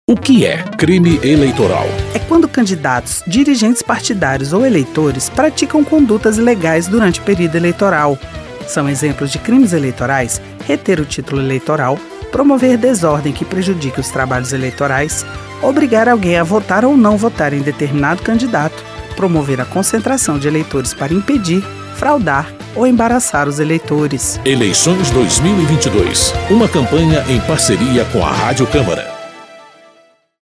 São 13 spots de 30 segundos.